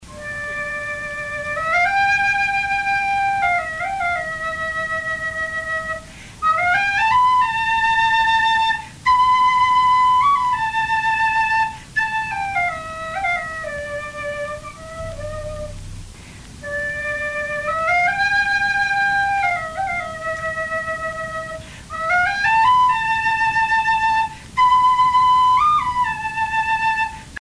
Istebna - Mélodie pour faire paître les vaches
Pièce musicale inédite